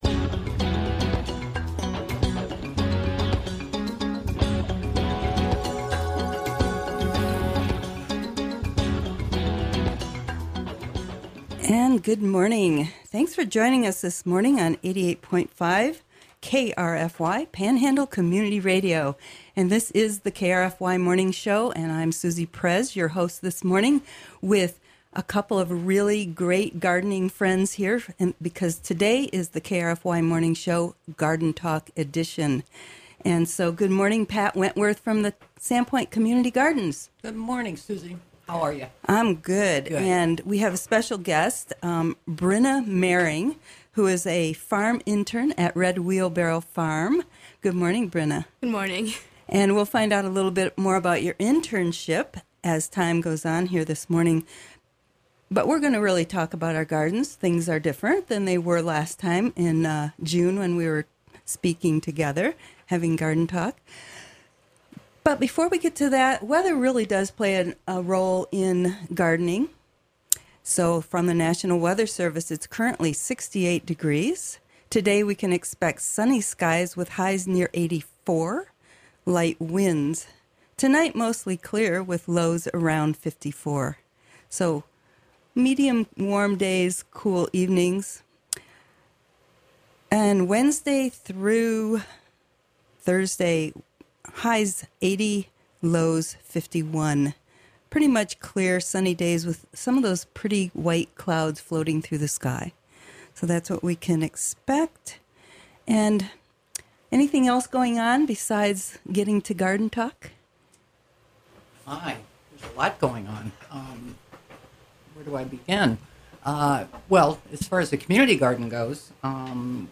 classical guitarist
guitar solo